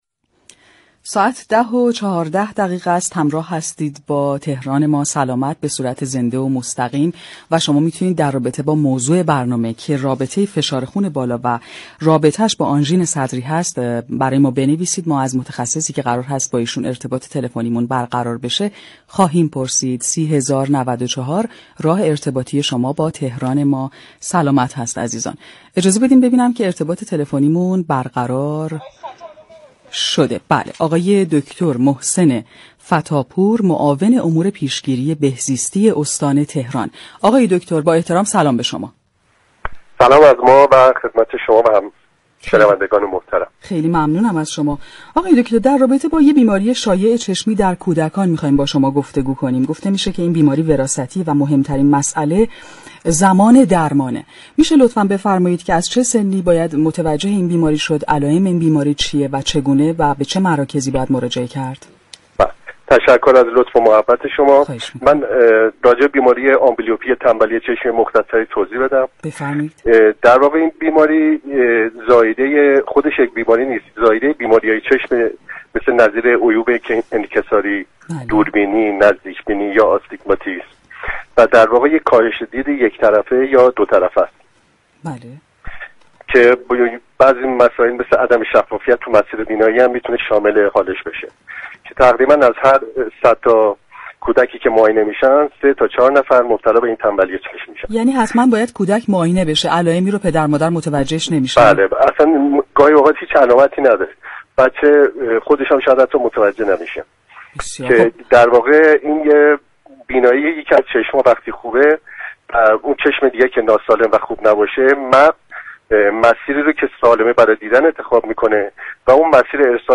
در گفتگو با برنامه تهران ما سلامت درباره بیماری تنبلی چشم گفت